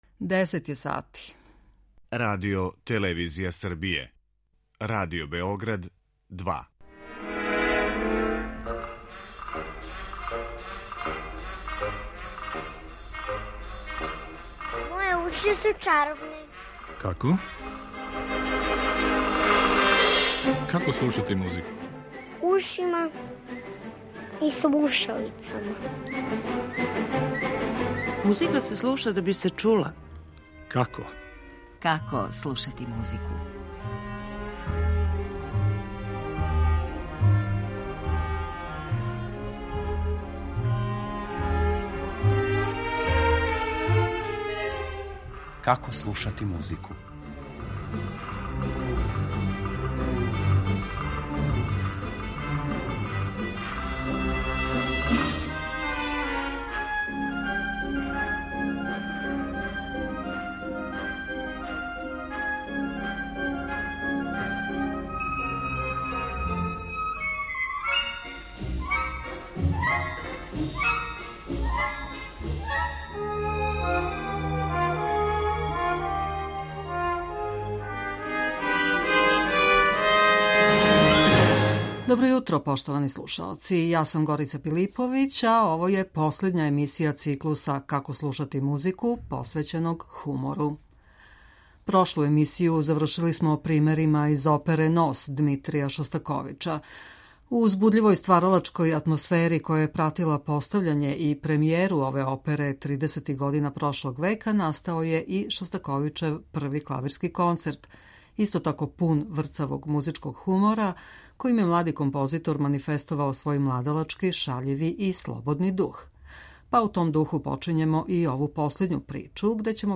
Последња емисија априлског циклуса Како слушати музику посвећеног хумору, доноси низ одабраних примера комичног садржаја из инструменталног жанра – симфонија, квартета, клавирских комада...